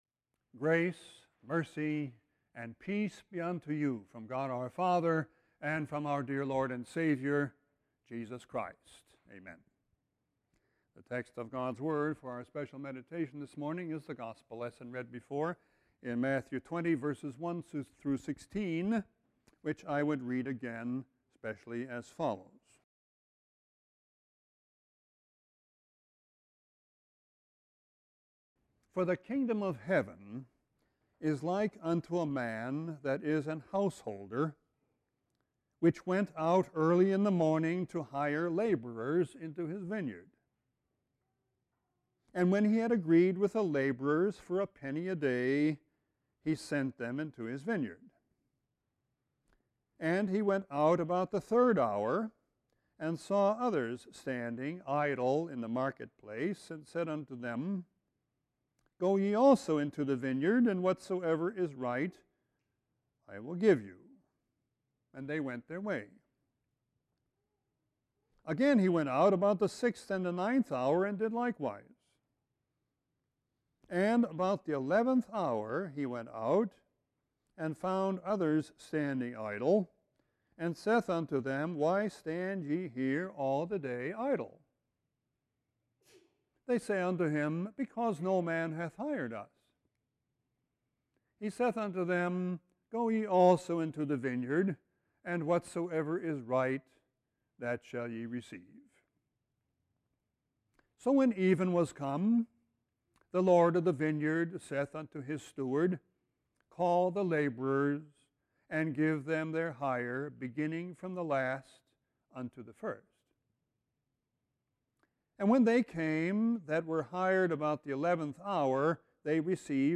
Sermon-1-27-13.mp3